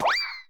Sound effect of Jump Board in Super Mario 3D World.
SM3DW_Jump_Board.oga